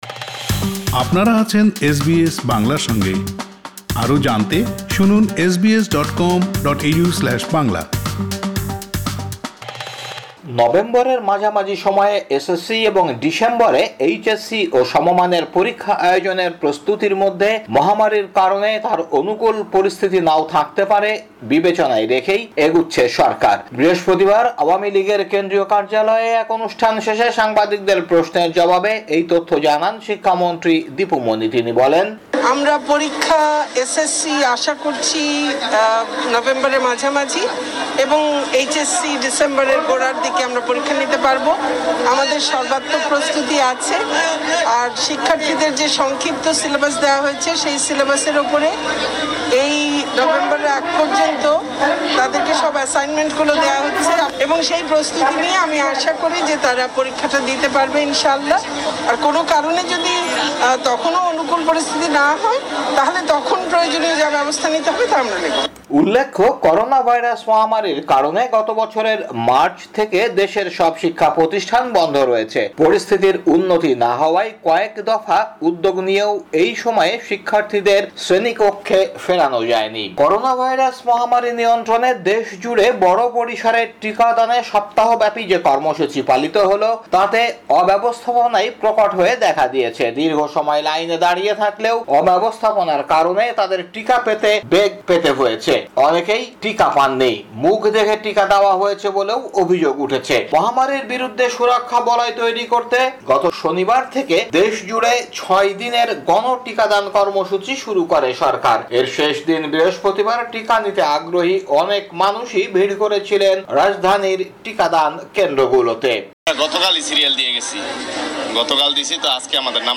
বাংলাদেশের সংবাদ: ১৪ আগস্ট ২০২১